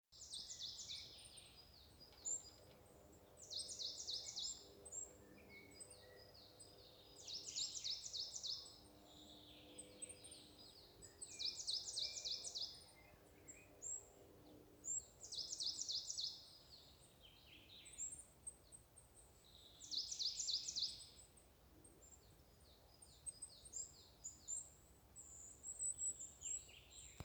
Coal Tit, Periparus ater
Administratīvā teritorijaVecumnieku novads
StatusSinging male in breeding season